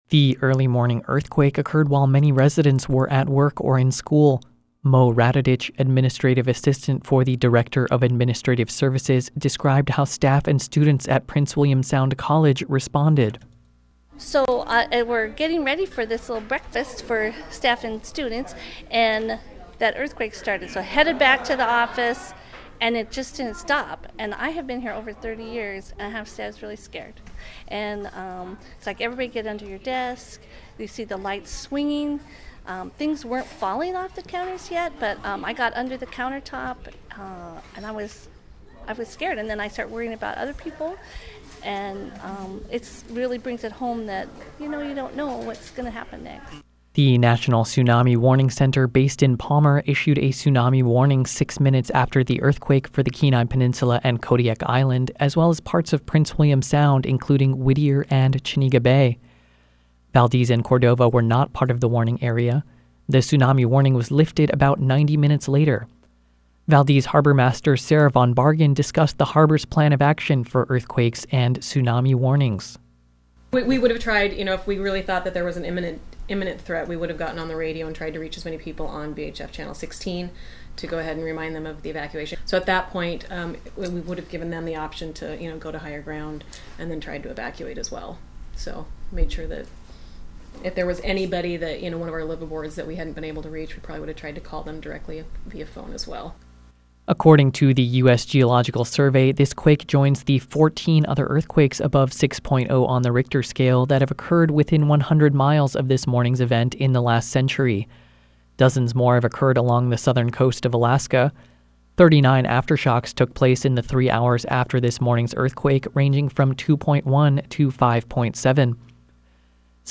KCHU News Audio-11/30 Earthquake